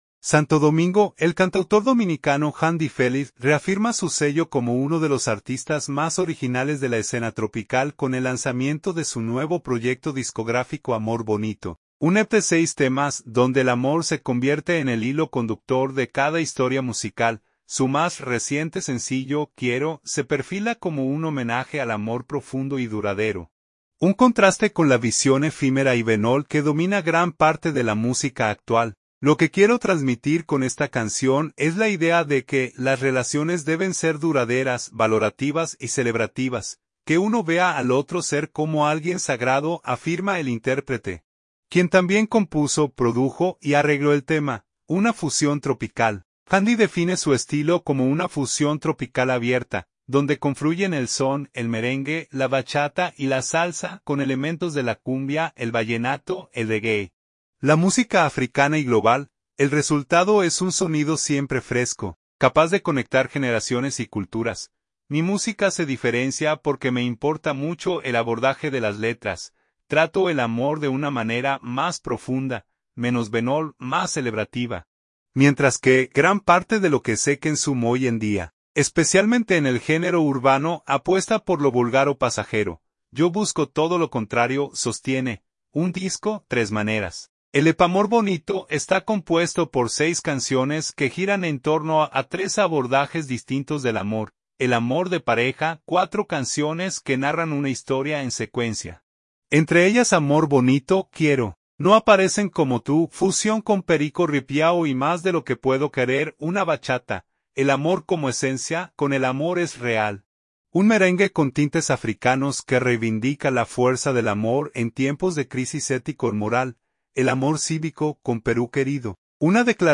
Una fusión tropical
al fusionar cumbia y son